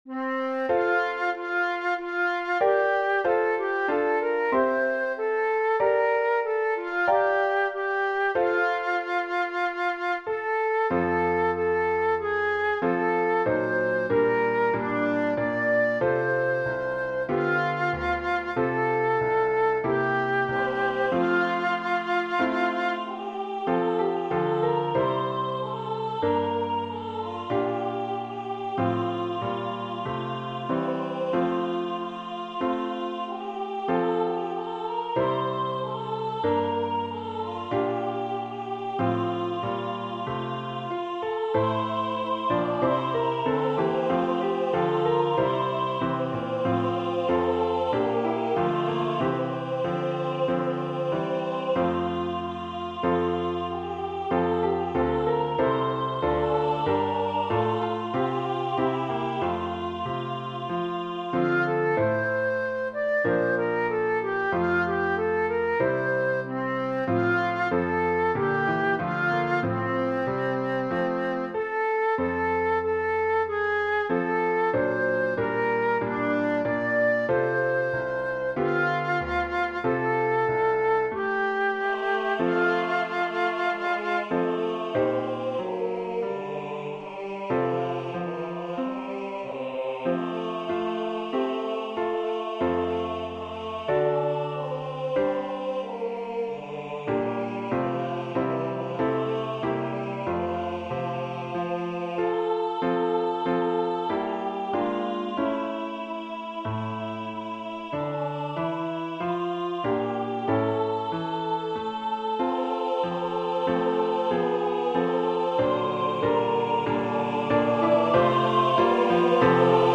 Oboe, SATB
The arrangement uses both hymntunes as both men and women parts collide on verse 3 and then on the pick up measure 60 the two tunes of their harmonies take turns on assigning the choir that is straight out of the hymnbook.
This arrangement also includes a flute part which an oboe can play the flute part if flute is not available.
Voicing/Instrumentation: SATB , Oboe We also have other 59 arrangements of " O Little Town Of Bethlehem ".